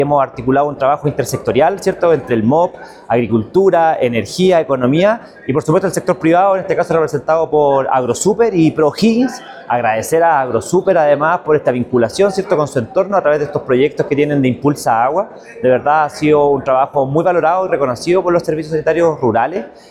Con la presencia de representantes del sector público y privado, instituciones académicas y directivos de Servicios Sanitarios Rurales (SSR), se llevó a cabo el primer Seminario Regional “Servicios Sanitarios Rurales de O’Higgins: Desafíos y Oportunidades hacia el 2036” en la sede de Inacap Rancagua.
Durante la actividad, el seremi de Economía de O’Higgins, Darío García, valoró la iniciativa como un ejemplo de la alianza público-privada señaló.
CUNA-1-Dario-Garcia-seremi-de-Economia.mp3